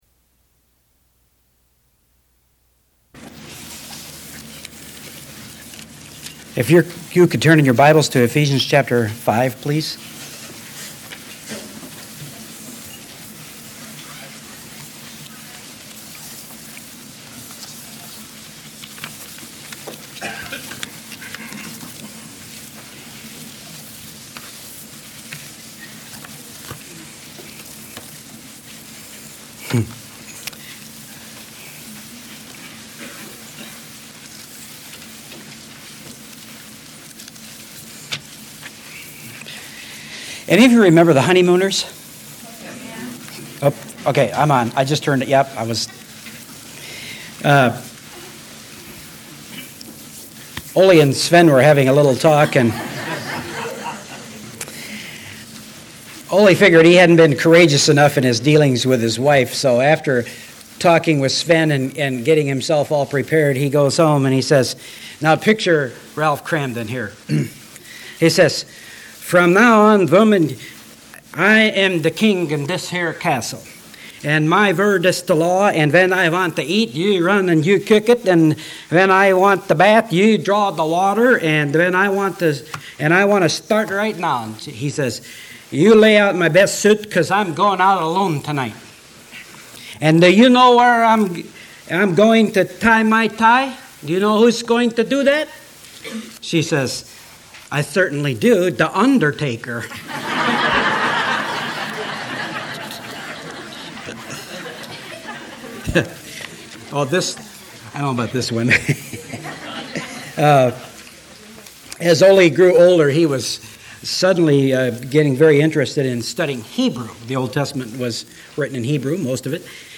Sermons | Fishing with Grace and Truth